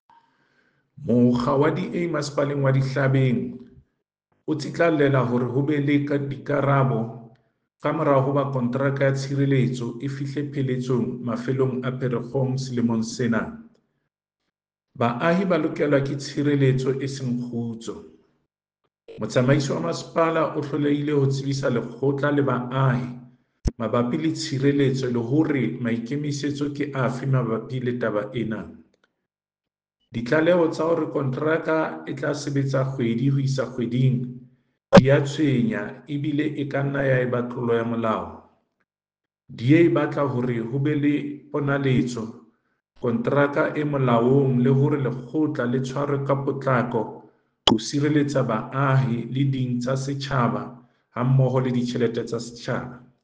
Sesotho soundbite by David Masoeu MPL.